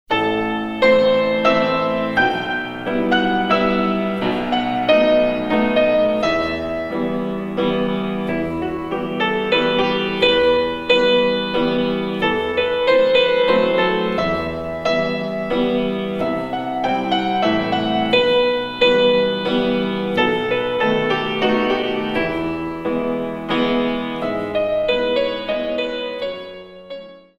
In 3
32 Counts